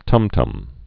(tŭmtŭm, tămtăm)